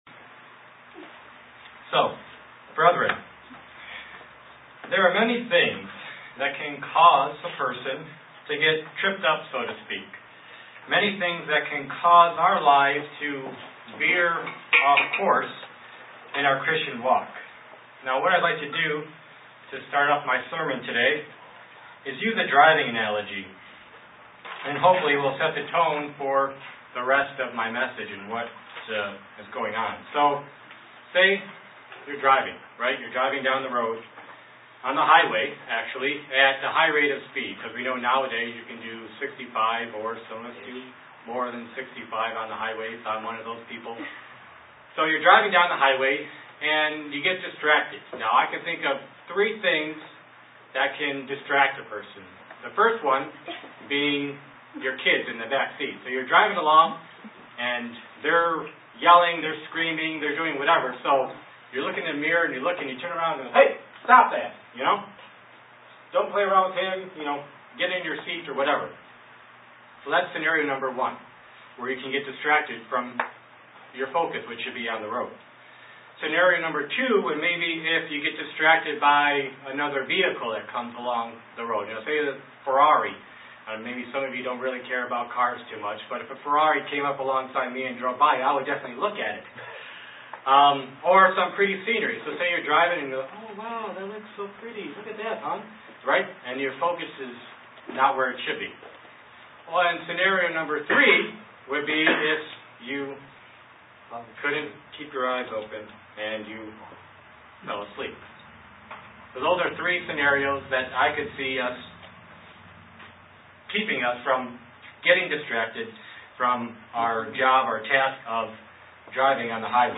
Given in Elmira, NY Buffalo, NY
UCG Sermon